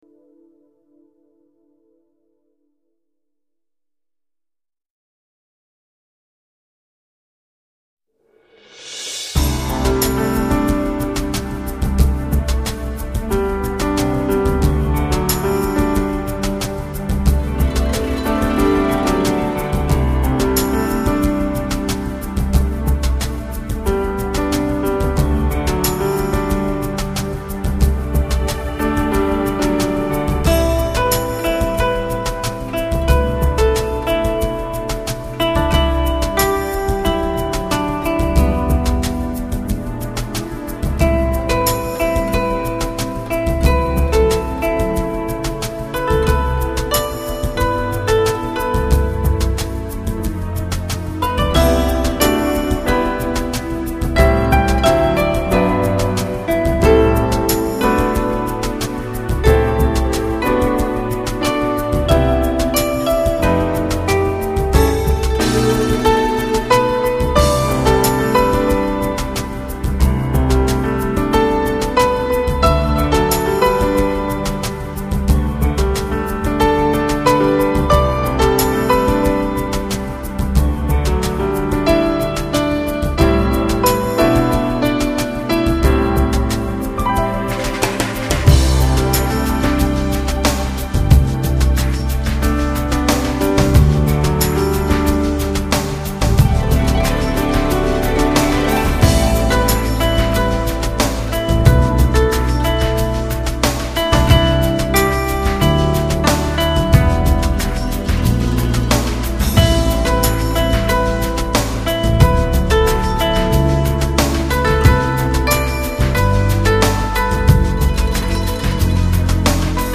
尺八 & 笛
Keyboard & Programming
本专辑是以亚洲风韵为主题的New Age专辑